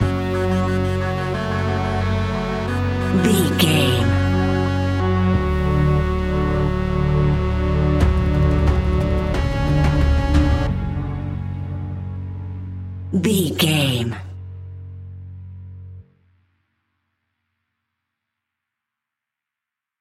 Aeolian/Minor
F#
ominous
dark
eerie
industrial
percussion
synthesiser
horror music